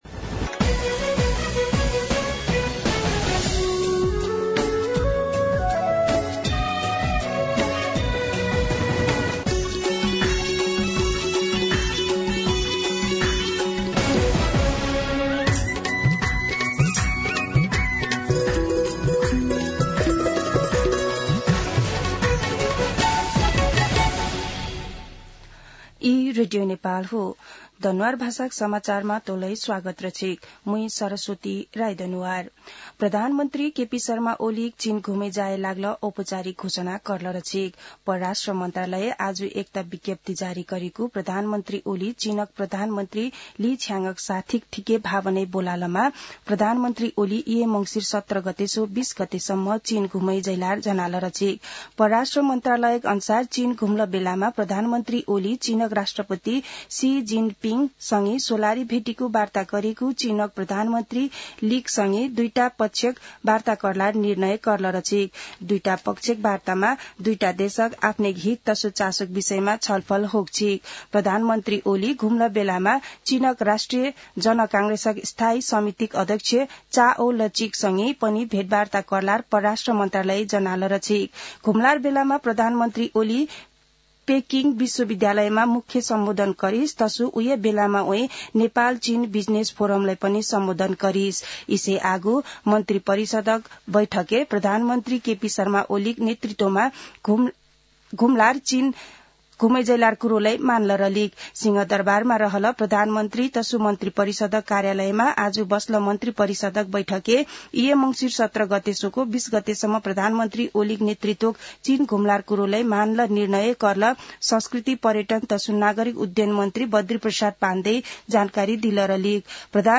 दनुवार भाषामा समाचार : १५ मंसिर , २०८१